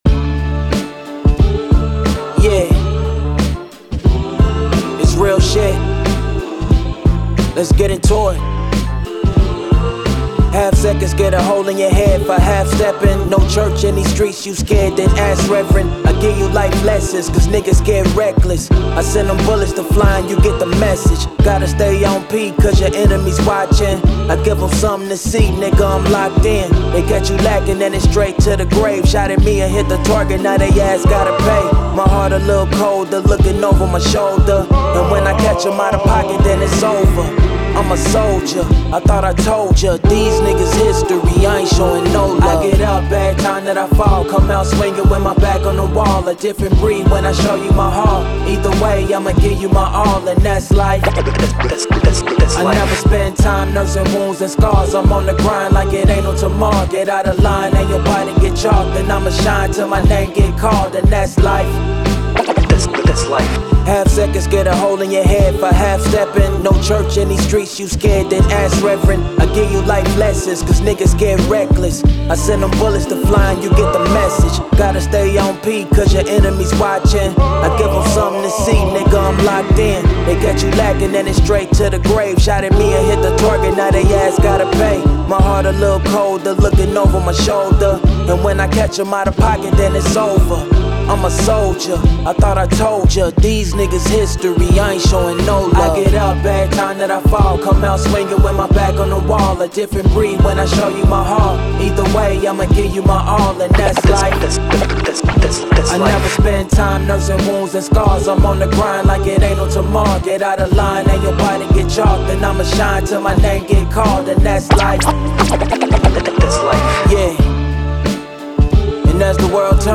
Hip Hop, 90s
D Minor